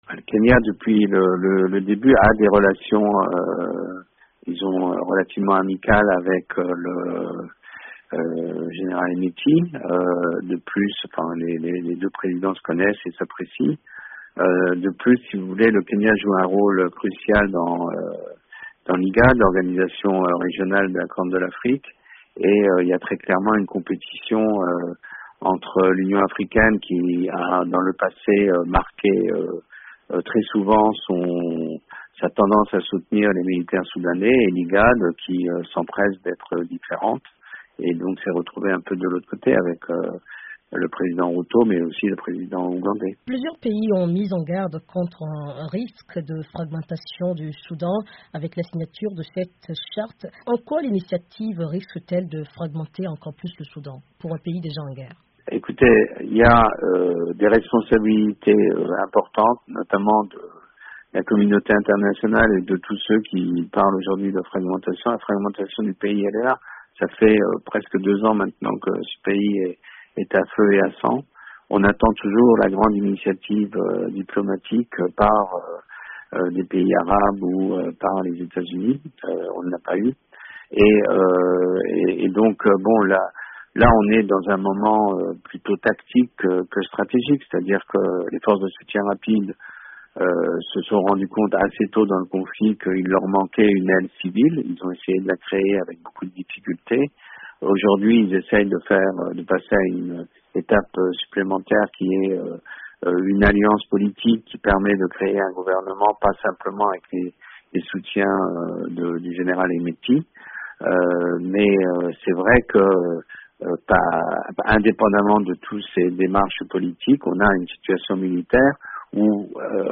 Les signataires du document s'engagent à construire un Etat laïque, démocratique, décentralisé, basé sur la liberté, l'égalité et la justice, sans parti pris culturel, ethnique, religieux ou régional. Pour une analyse